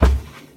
assets / minecraft / sounds / mob / cow / step3.ogg